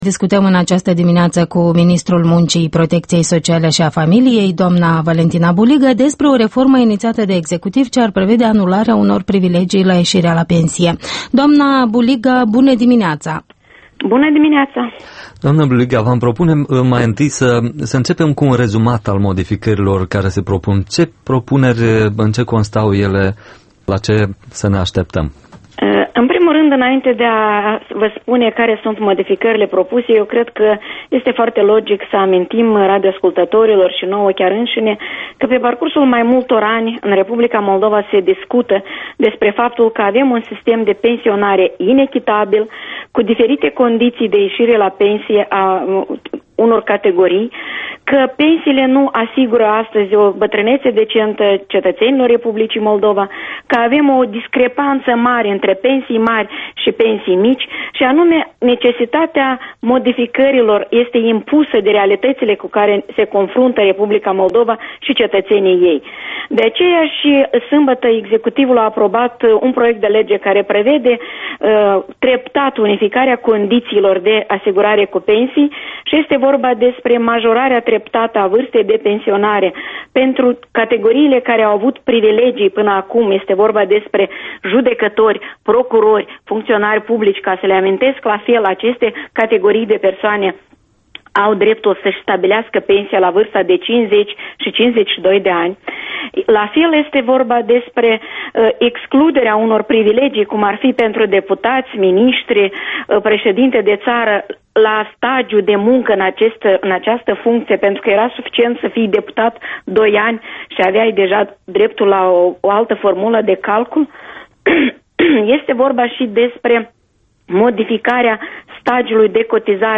Interviul matinal EL: cu Valentina Buliga despre reforma sistemului de pensii